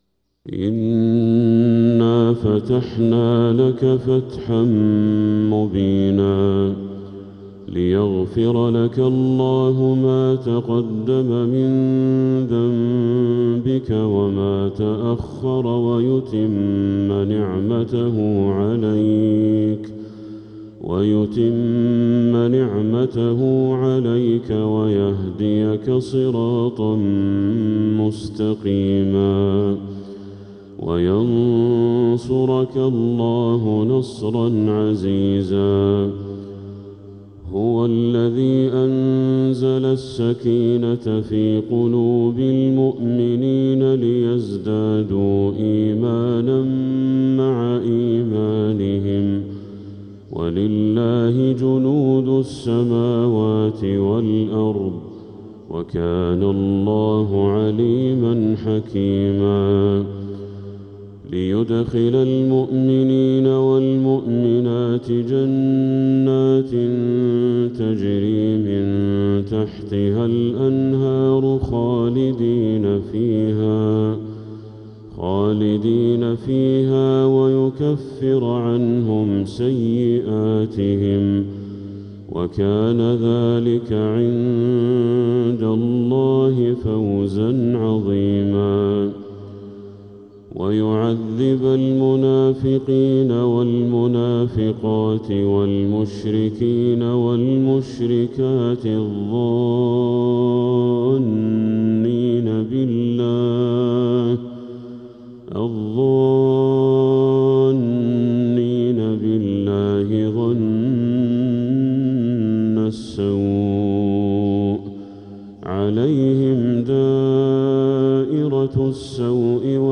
سورة الفتح كاملة للشيخ بدر التركي من المسجد الحرام > السور المكتملة للشيخ بدر التركي من الحرم المكي 🕋 > السور المكتملة 🕋 > المزيد - تلاوات الحرمين